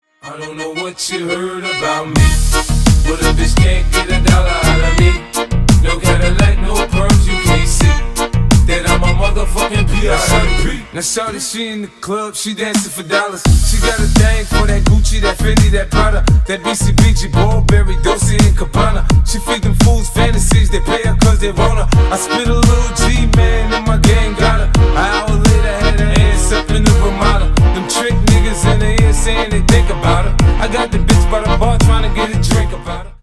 Громкие рингтоны , Танцевальные рингтоны